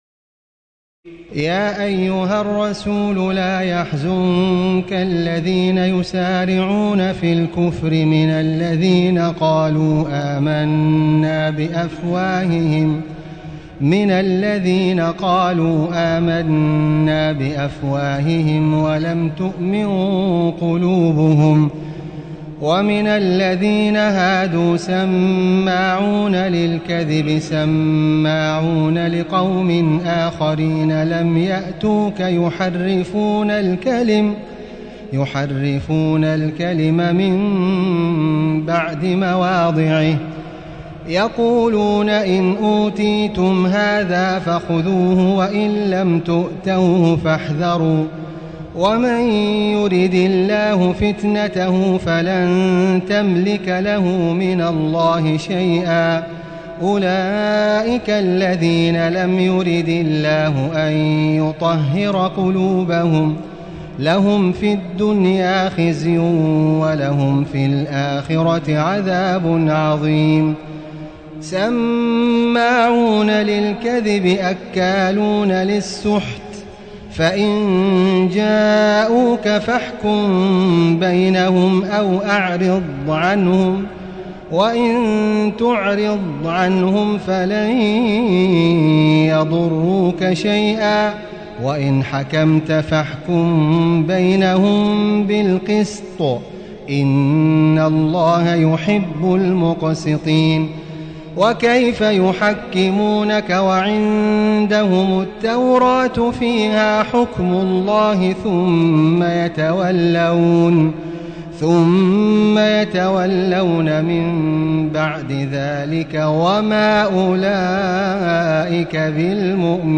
تراويح الليلة السادسة رمضان 1437هـ من سورة المائدة (41-104) Taraweeh 6 st night Ramadan 1437H from Surah AlMa'idah > تراويح الحرم المكي عام 1437 🕋 > التراويح - تلاوات الحرمين